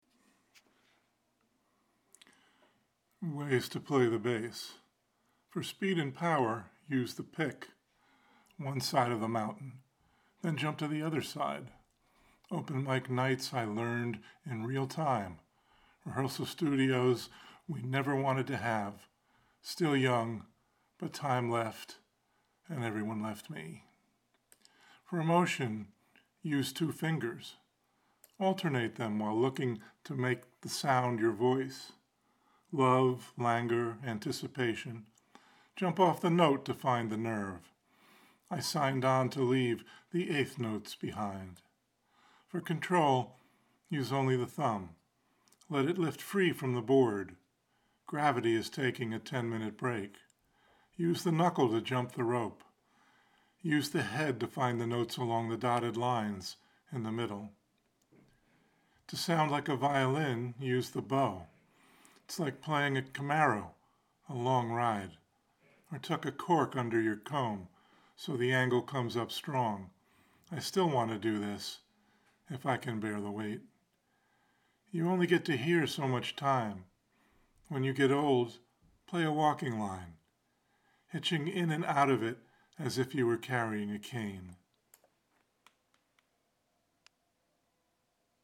The Sunday Poem  is published weekly, and strives to include the poet reading their work.